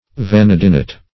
Vanadinite \Va*nad"i*nite\, n. (Min.)